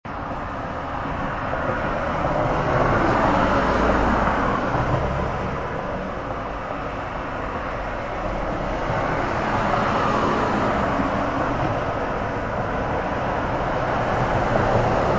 Note that the samples are mp3 (lower quality than the CD) to keep download times short.
And as you rest, so close that you can almost touch them, your fellow travelers continue on, tires humming and engines purring, back and forth to unknown destinations. As you listen carefully, you can hear that they move with varying tempos, sometimes in groups, sometimes apart.